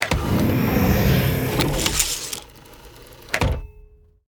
doorclose4.ogg